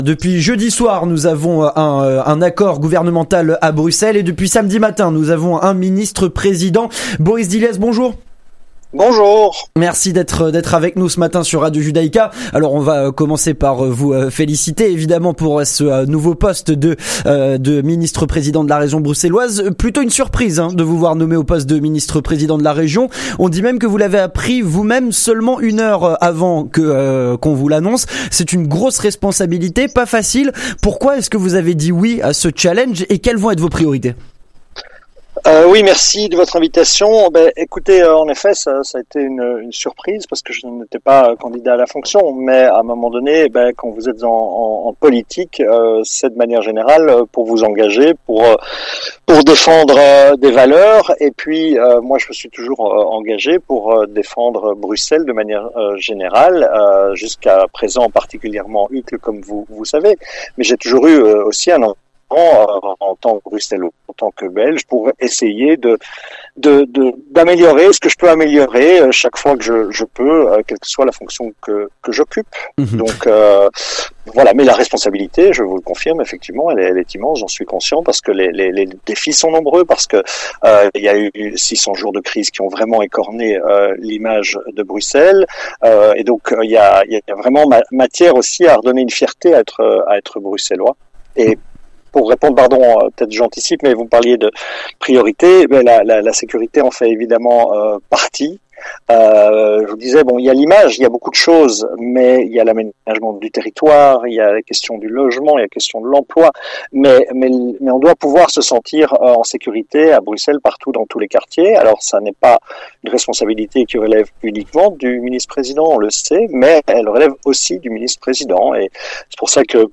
Ce nouveau Ministre-Président, c'est Boris Dilliès, ancien bourgmestre de la commune d'Uccle.